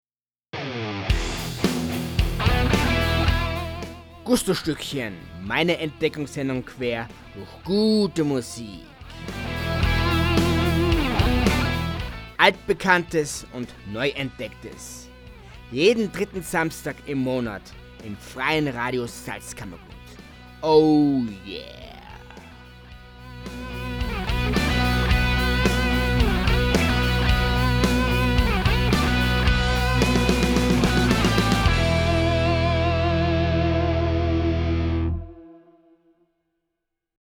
Sendungstrailer
FRS-TRAILER-GUSTOSTUeCKCHEN-3-SAMSTAG.mp3